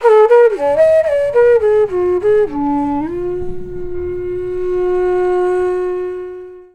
FLUTE-A12 -L.wav